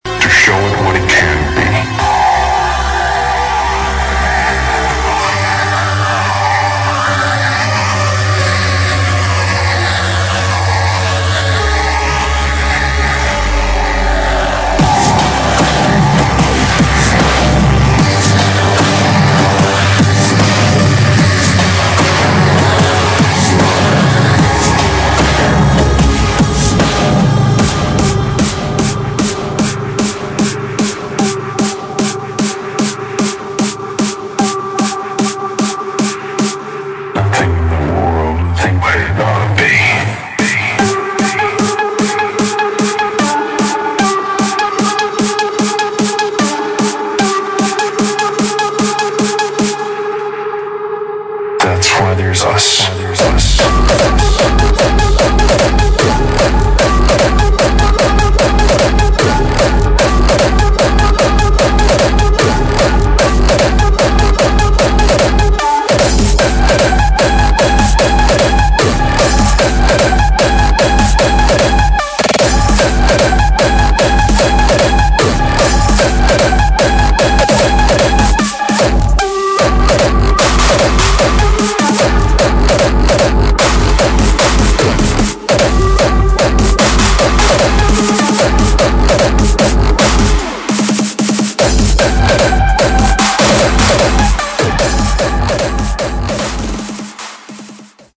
[ HARDCORE ]